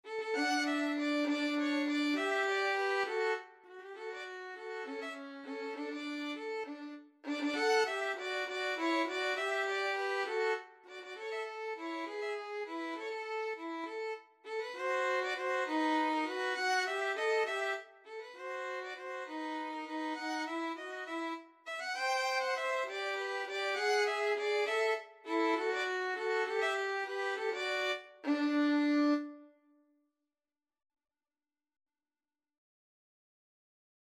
Free Sheet music for Violin Duet
D major (Sounding Pitch) (View more D major Music for Violin Duet )
3/8 (View more 3/8 Music)
Classical (View more Classical Violin Duet Music)